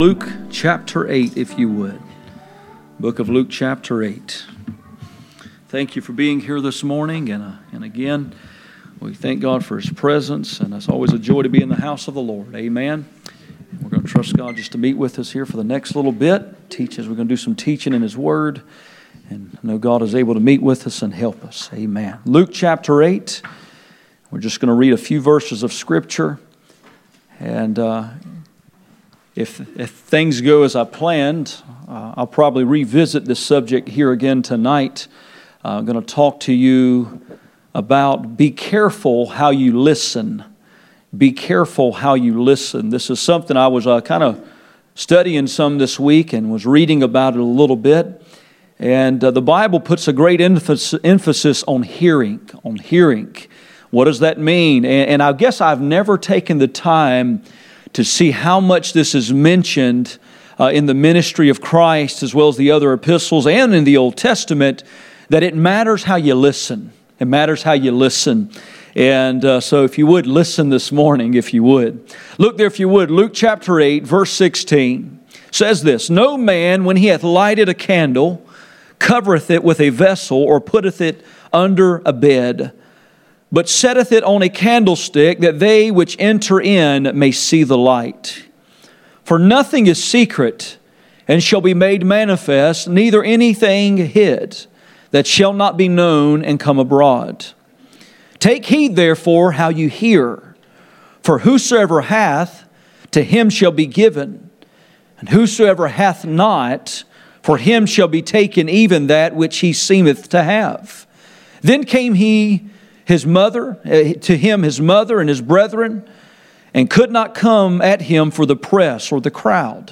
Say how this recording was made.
Luke 8:16-21 Service Type: Sunday Morning %todo_render% « Five aspects of Gods Love Be care how you listen